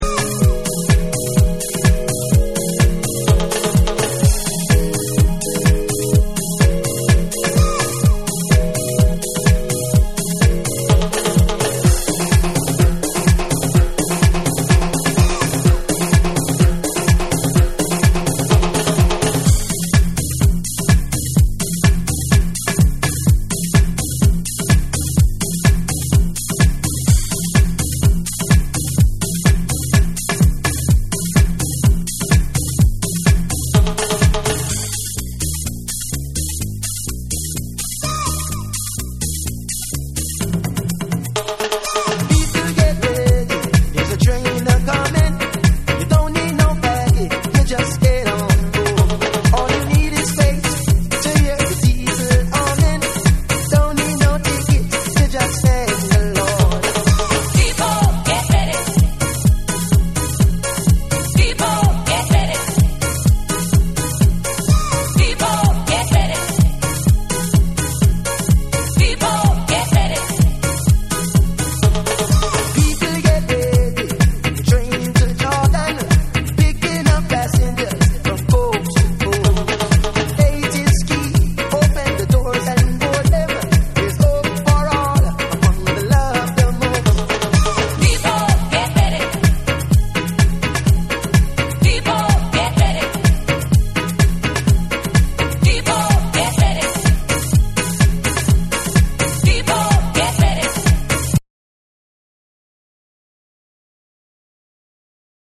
ソウルフルな歌声が、トライバルなティンパレスをアクセントに使ったスムースなハウス・トラックに裏打ちが刻まれる1
BREAKBEATS / REGGAE & DUB